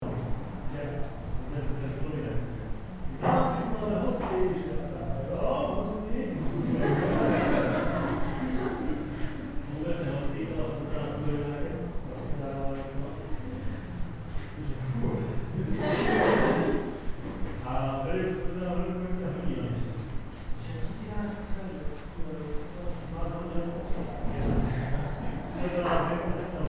Empty-school-hallway-quiet-ambience-loop-2.mp3